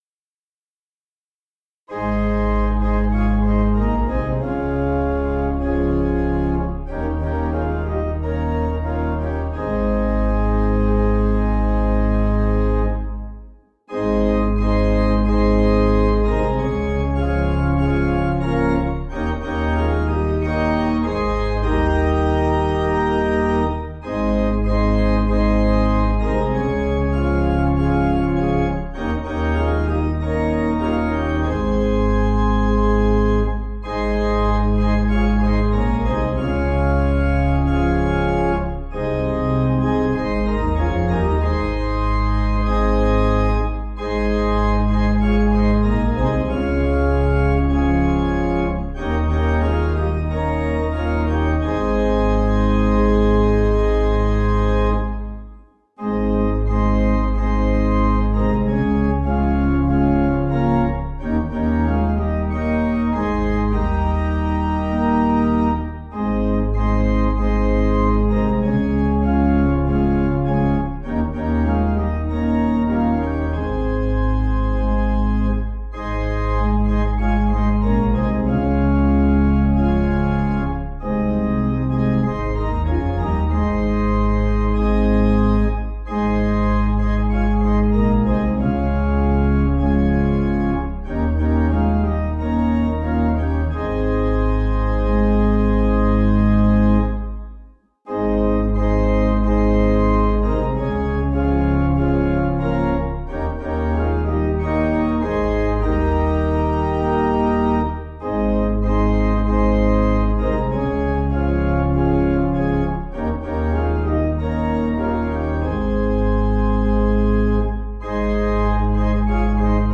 Organ
(CM)   4/Ab